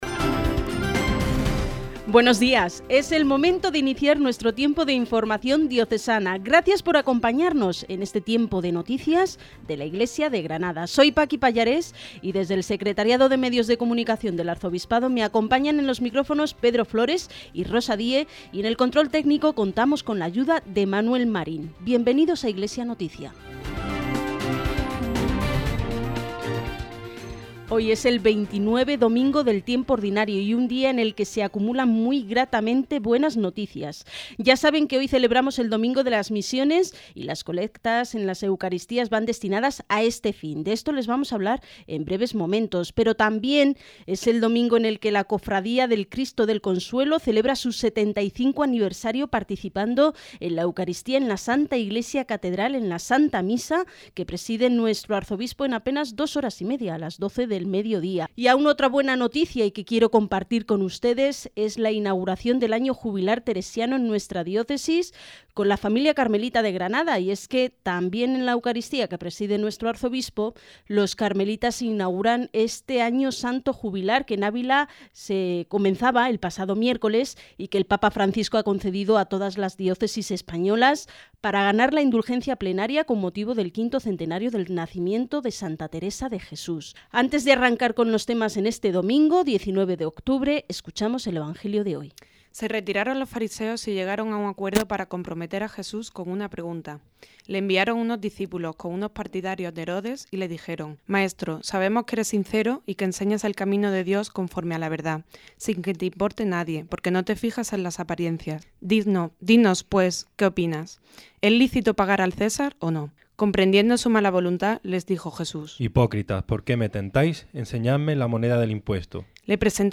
Programa informativo que realiza el Secretariado de Medios de Comunicación Social del Arzobispado de Granada, emitido en COPE Granada el domingo 19 de octubre de 2014.